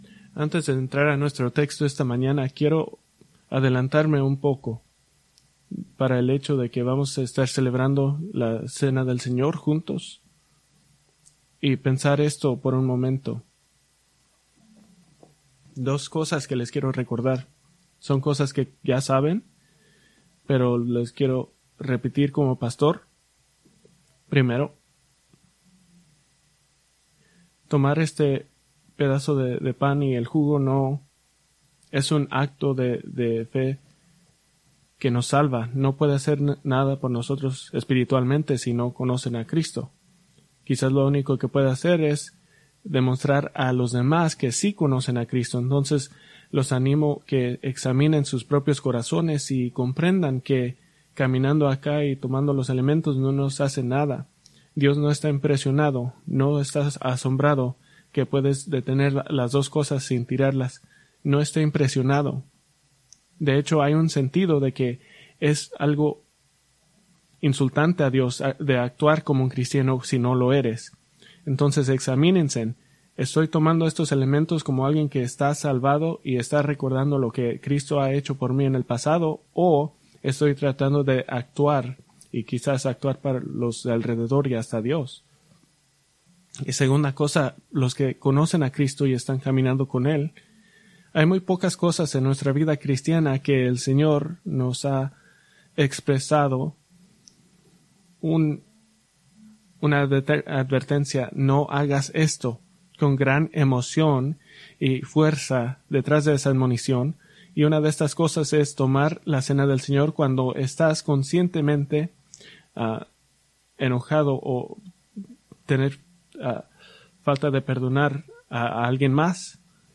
Preached November 10, 2024 from Mateo 9:9-17